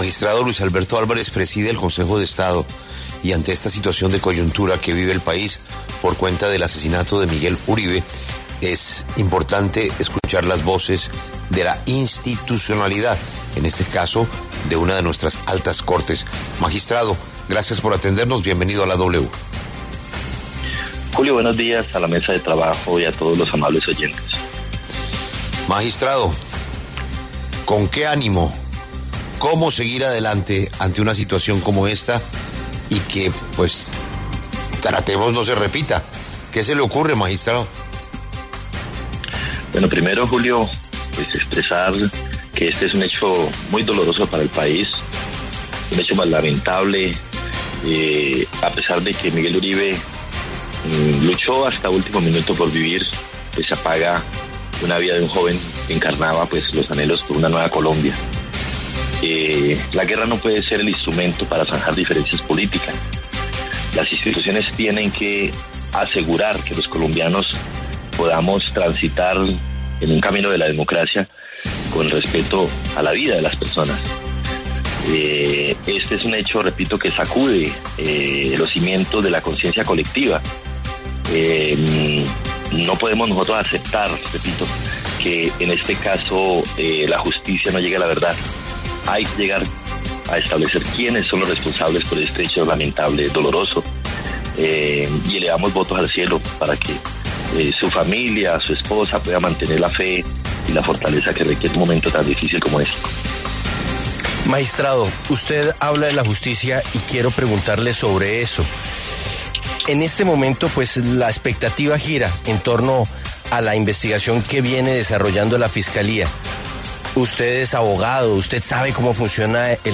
Este lunes, 11 de agosto, habló en los micrófonos de La W, con Julio Sánchez Cristo, el magistrado Luis Alberto Álvarez, presidente del Consejo de Estado, quien se pronunció sobre la muerte del senador y precandidato presidencial Miguel Uribe, luego de dos meses de sufrir un atentado en Bogotá.